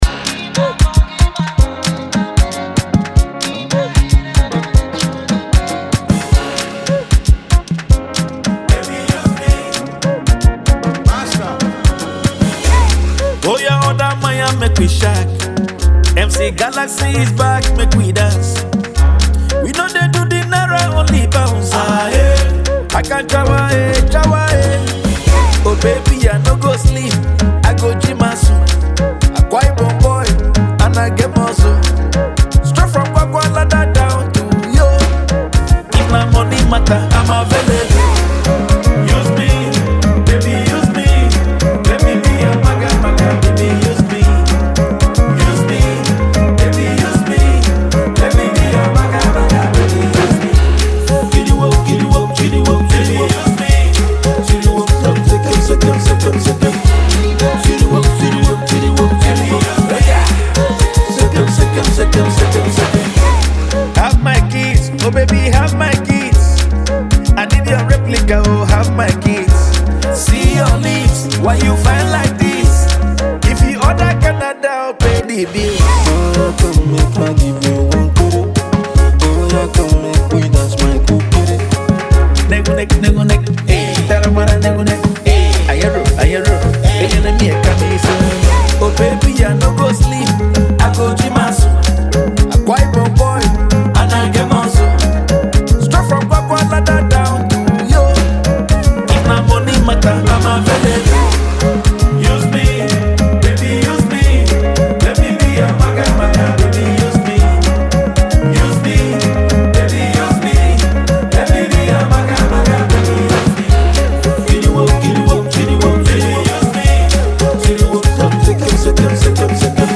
a jam for your weddings and other important ceremonies